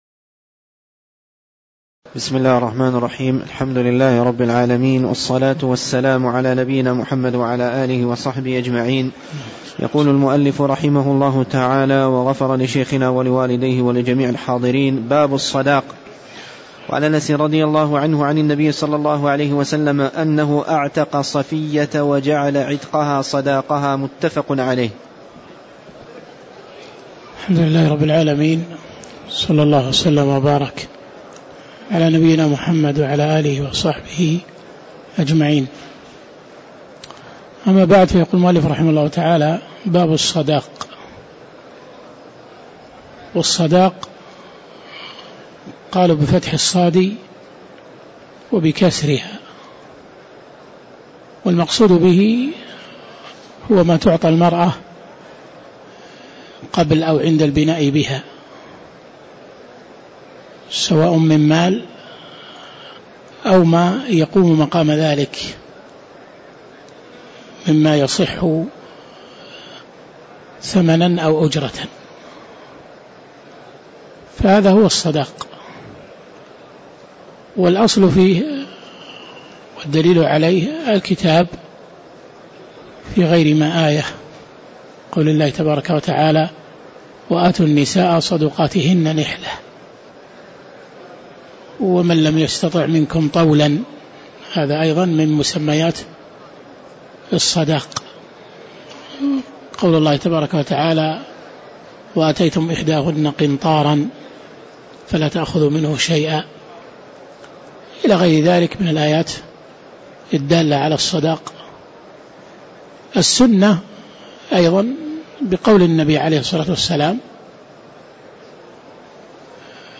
تاريخ النشر ٢٧ جمادى الآخرة ١٤٣٧ هـ المكان: المسجد النبوي الشيخ